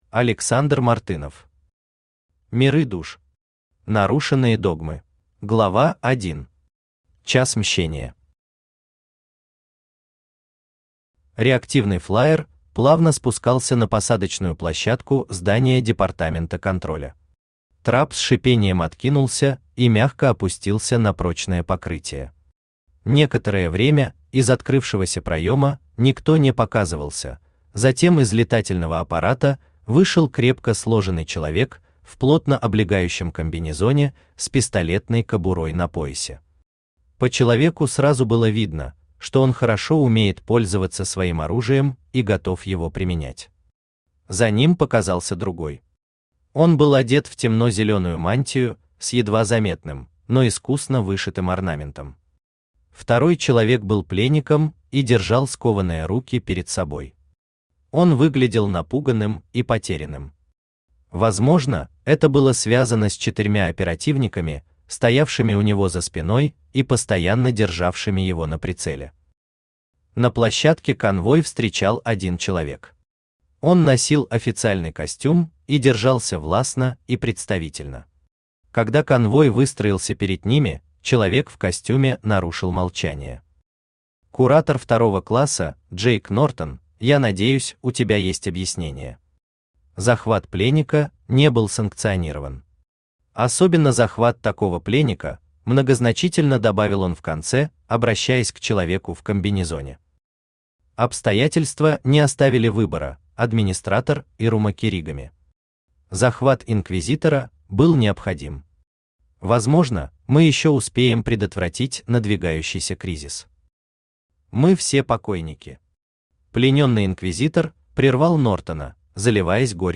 Нарушенные догмы Автор Александр Мартынов Читает аудиокнигу Авточтец ЛитРес.